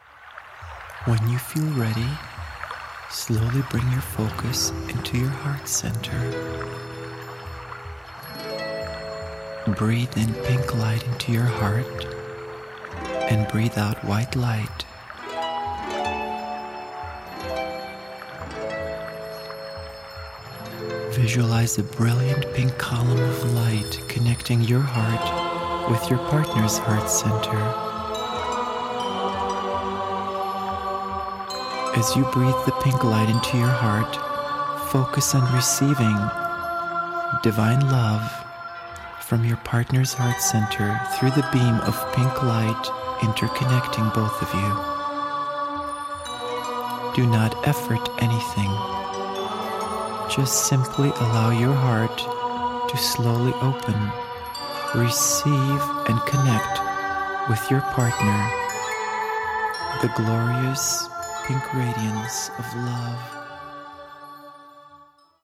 A dynamic digital download from a 2 CD set featuring 2 powerful meditations & visualization exercises designed to open and heal your heart, and to prepare you for your true love.
MAGNETIZING-2.-Dynamic-Chakra-Meditation.mp3